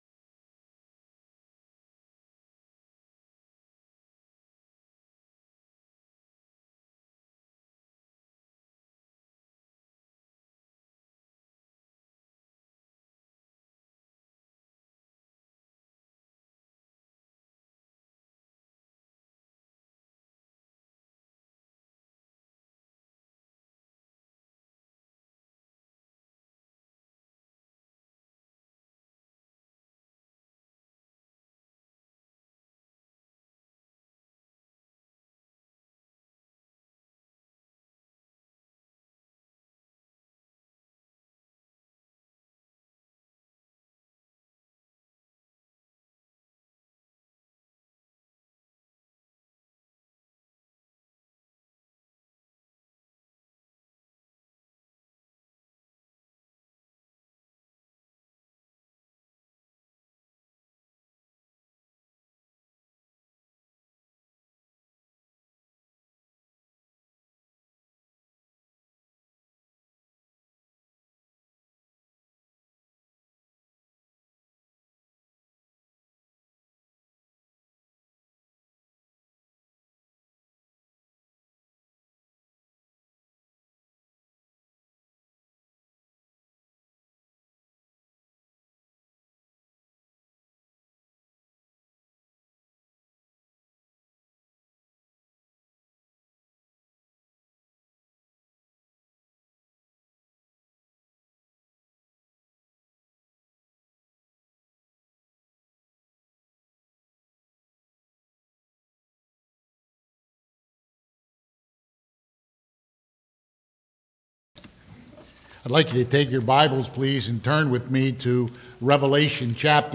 Revelation 1:1-20 Service Type: Sunday AM « September 15